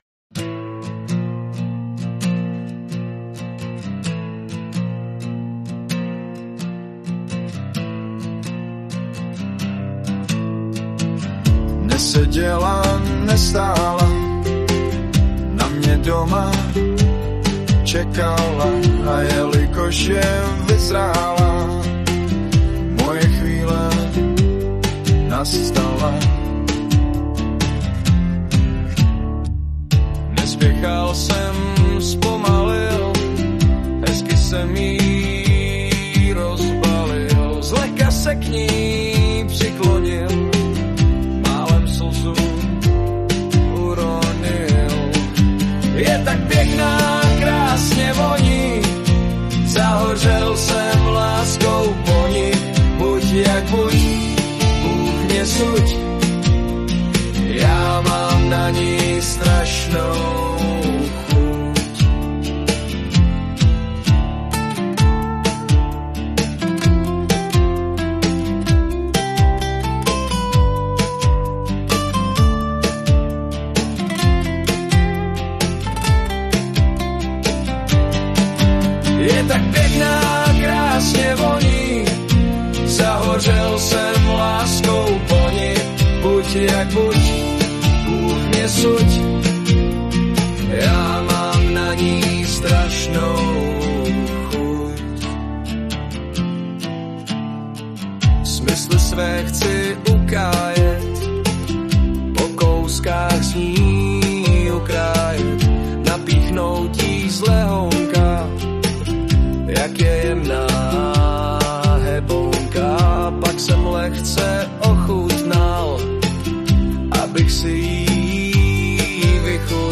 Anotace: Zhudebněno pomoci AI.